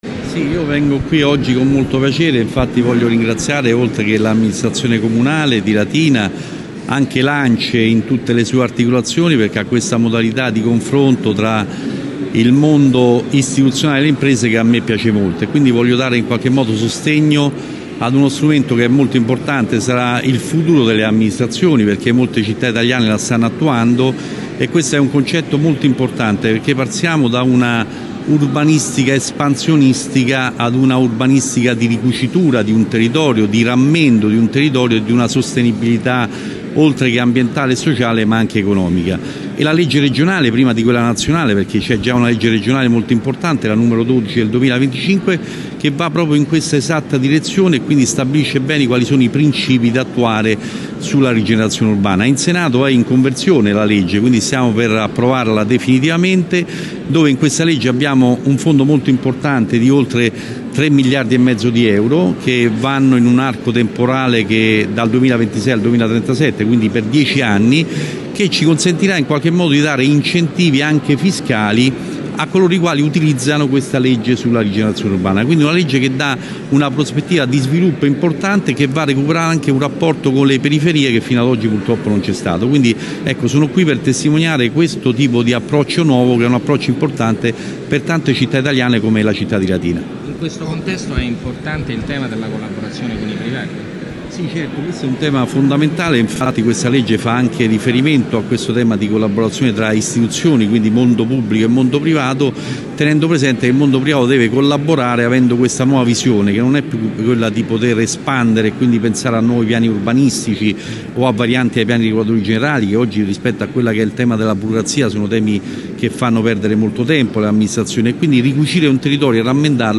La tappa di Latina che andrà avanti per tutta la giornata è iniziata alle 10 al Museo Cambellotti.
Ad introdurre i lavori, articolati in una sessione mattutina e una pomeridiana, anche il presidente della 5ª Commissione Bilancio del Senato Nicola Calandrini